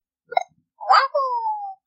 Yoshi's sound clip when selected with a Wii Remote.
Yoshi_Wiimote_Sound.ogg.mp3